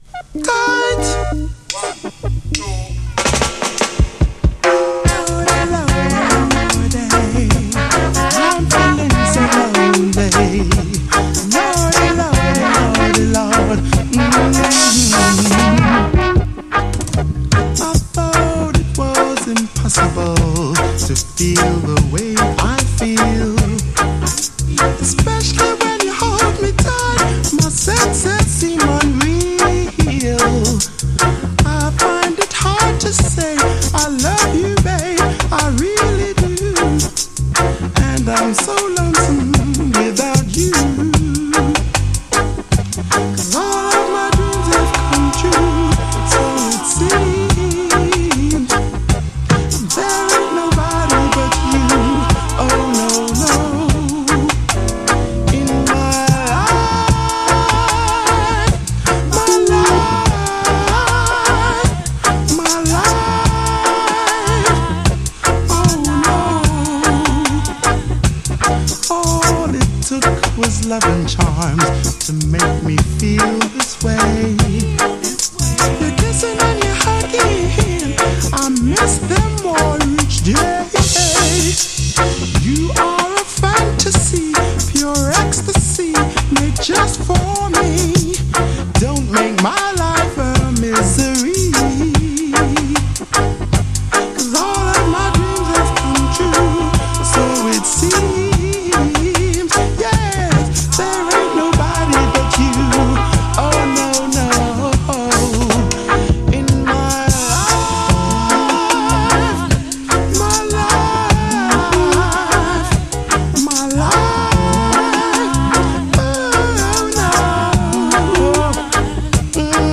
REGGAE
生バンド・サウンド全開の音の雰囲気から推測するにおそらく80’S前半頃までの録音と思われます。